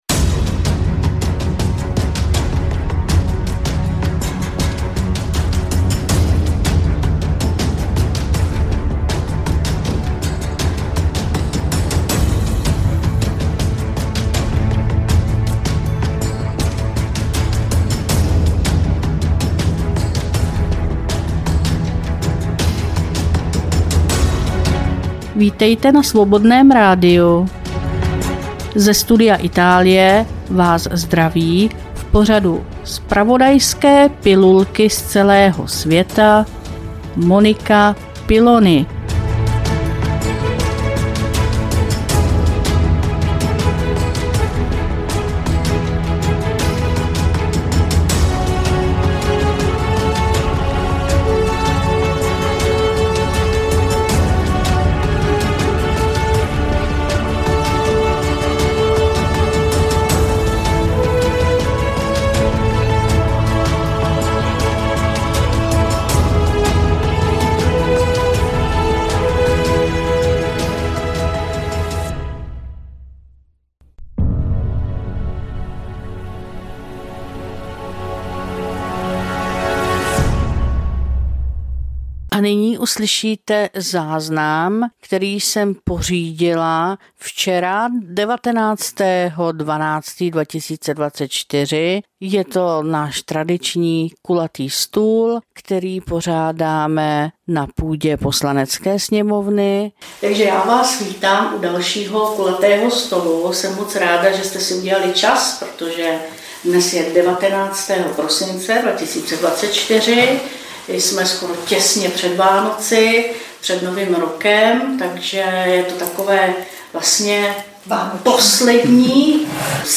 Studio Itálie – Kulatý stul na půdě poslanecké sněmovny